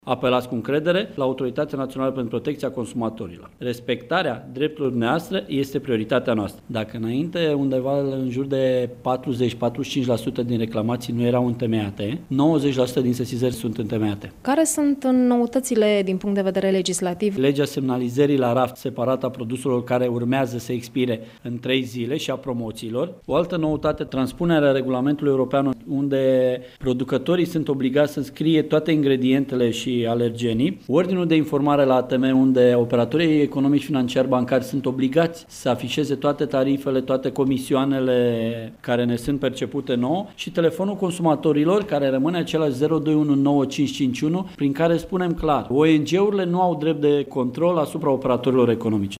Într-un dialog